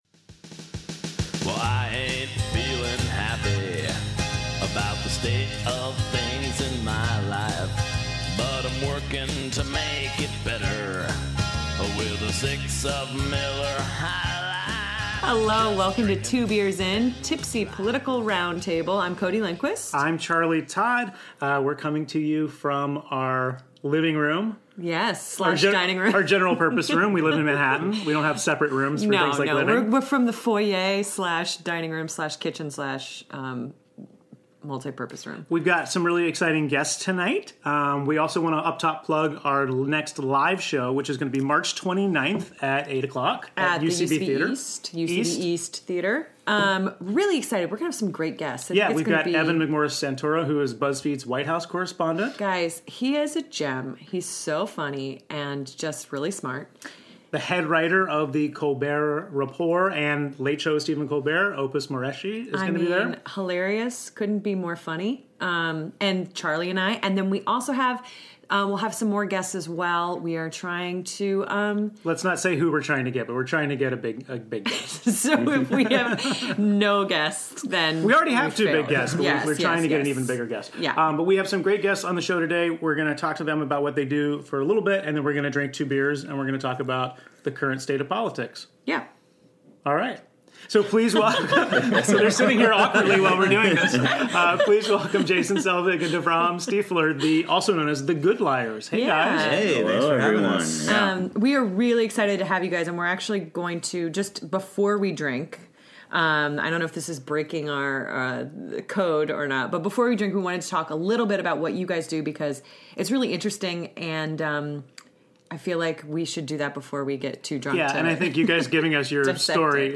We talk to the Good Liars about their incredible political pranks and hoaxes and then chug some Founders All Day IPA and get into the politics of the week. Recorded in our Hell's Kitchen apartment on March 3, 2016.